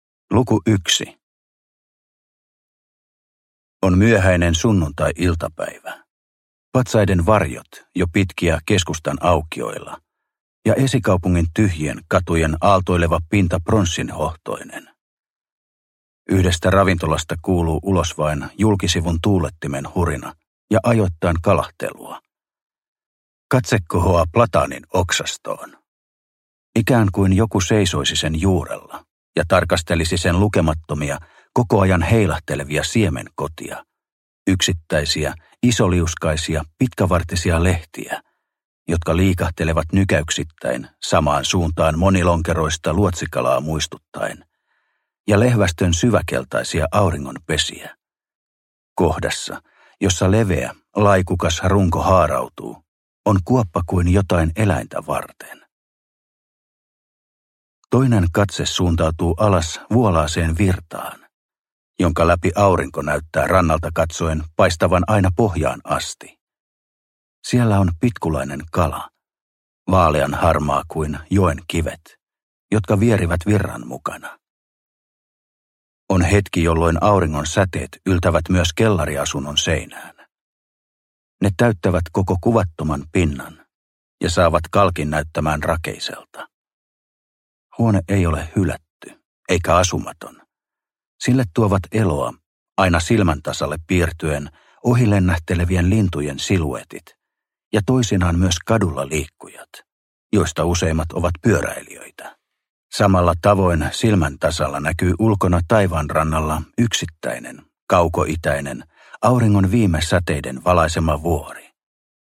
Poissa – Ljudbok – Laddas ner